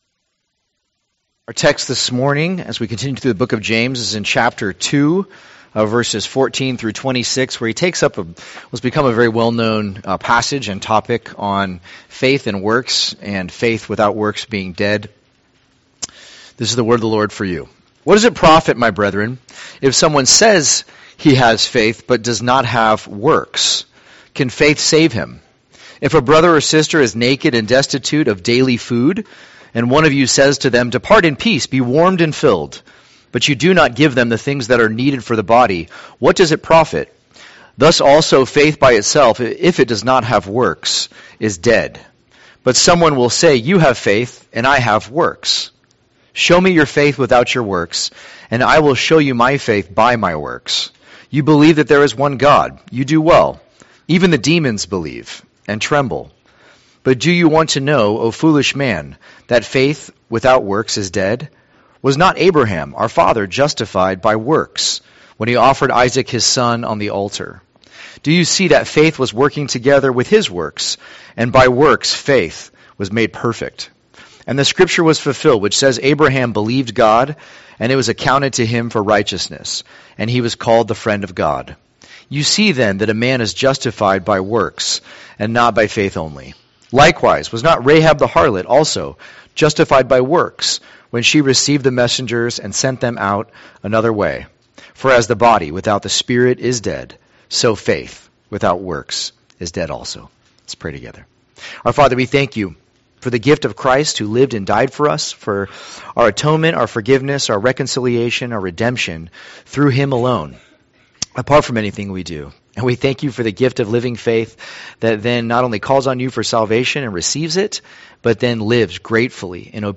2024 Living Faith Preacher